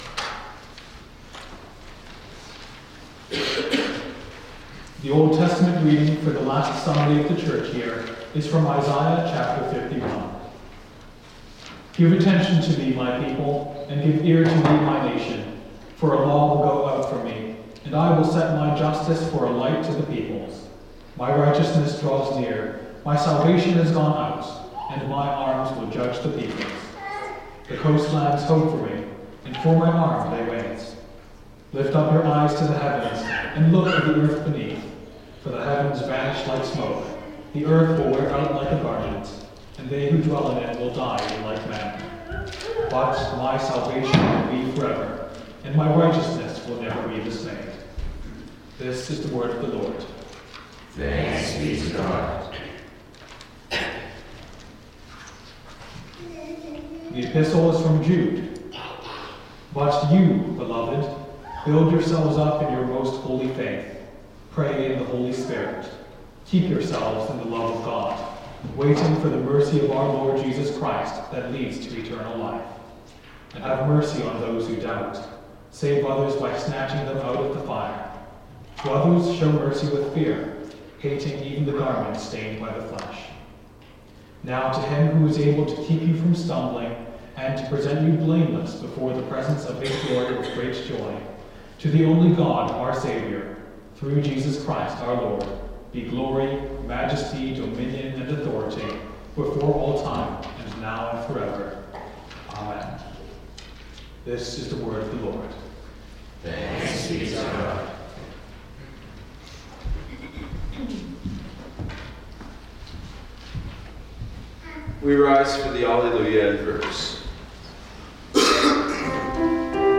Readings and Sermon – November 24, 2024
Last Sunday of the Church Year November 24, 2024